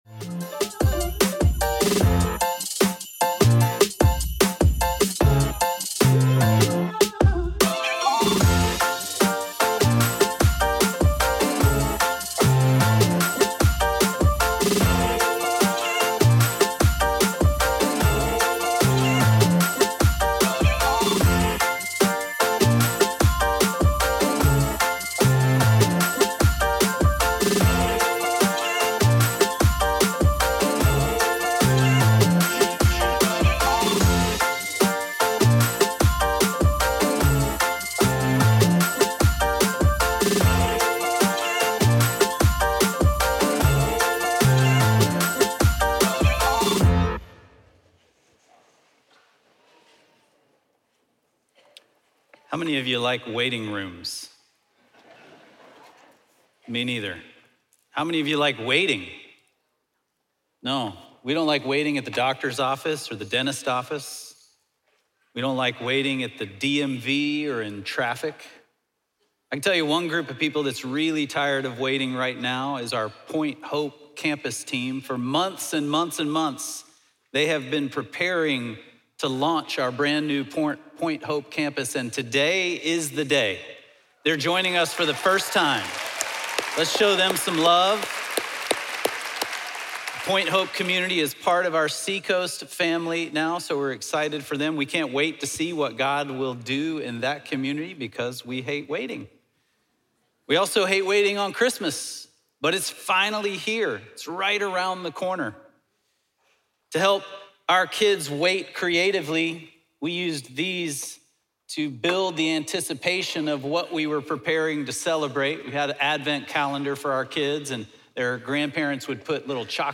1 The CISO Holiday Party 2025: Leadership Lessons from the Year That Was - BSW #427 49:27 Play Pause 1d ago 49:27 Play Pause Play later Play later Lists Like Liked 49:27 Join Business Security Weekly for a roundtable-style year-in-review. The BSW hosts share the most surprising, inspiring, and humbling moments of 2025 in business security, culture, and personal growth.